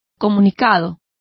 Complete with pronunciation of the translation of communication.